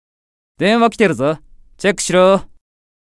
File:Mammon Call Notification Voice.ogg
Mammon_Call_Notification_Voice.ogg.mp3